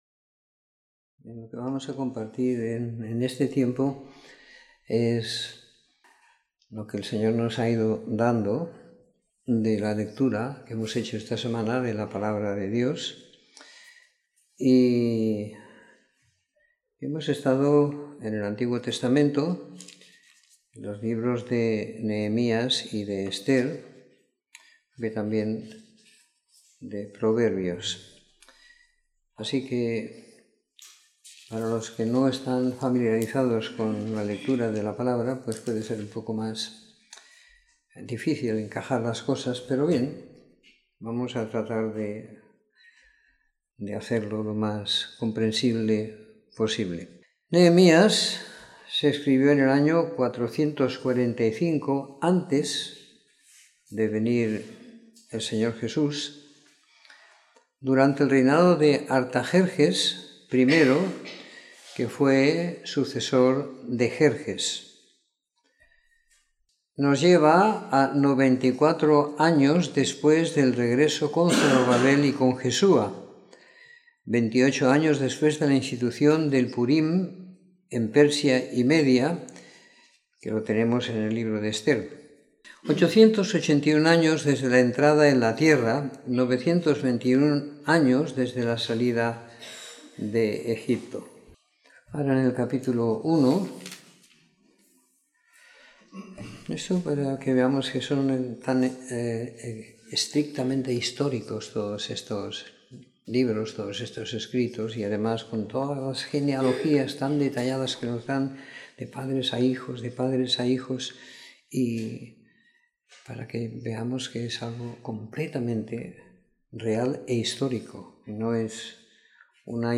Comentario en los libros de Nehemías y Ester siguiendo la lectura programada para cada semana del año que tenemos en la congregación en Sant Pere de Ribes.